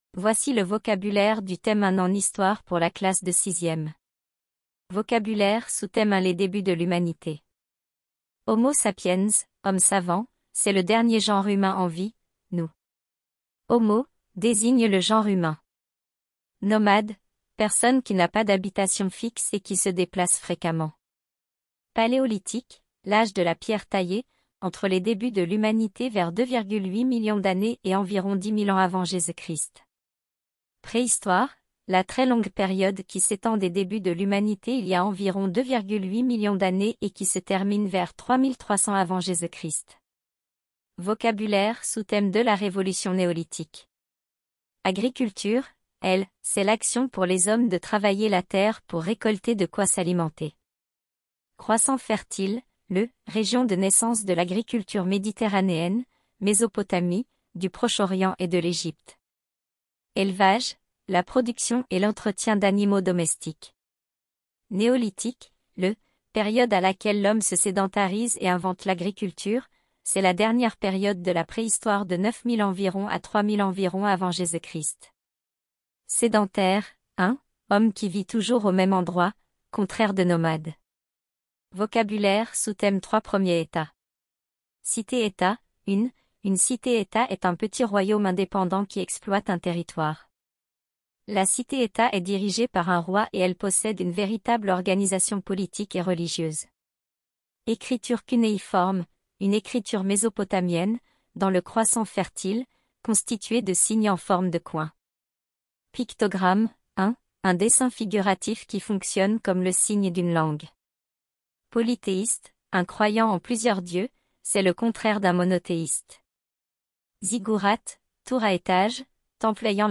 Cours audio 6e histoire